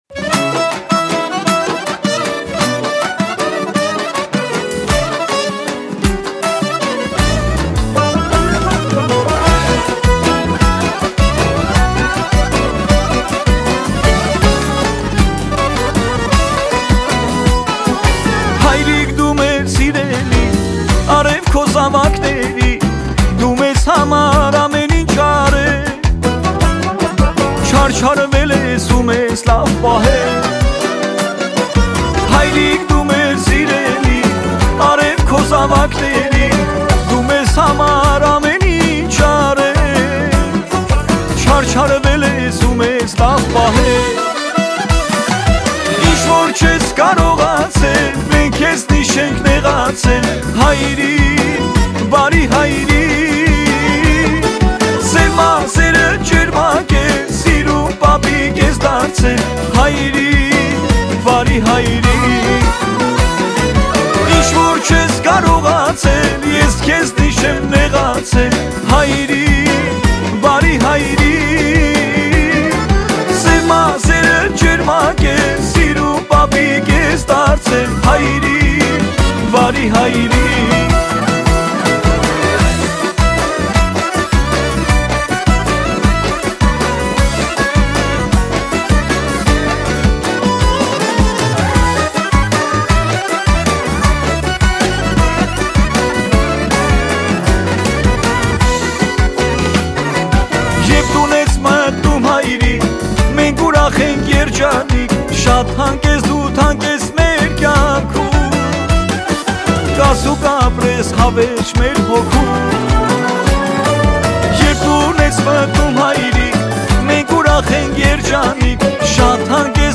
Армянская музыка, Erger 2018, Семейный